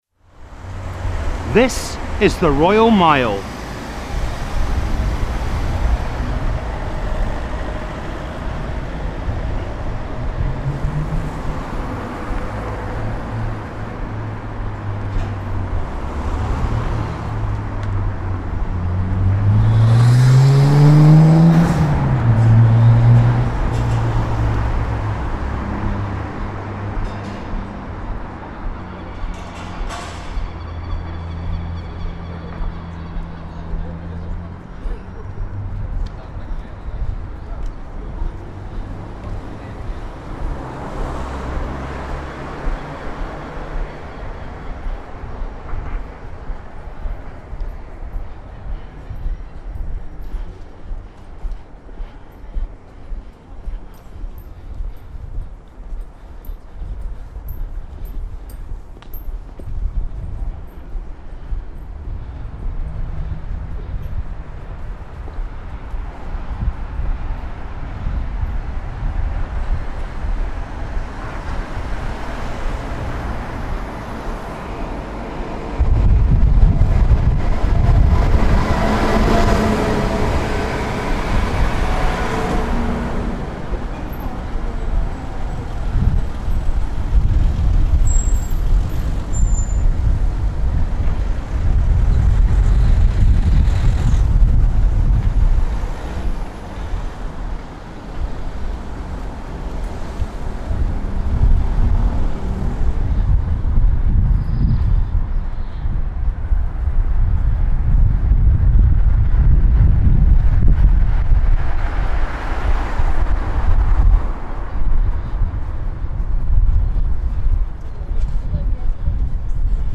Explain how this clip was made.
Street Sounds of Scotland: The Royal Mile royal-mile.mp3